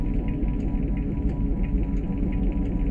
whir.wav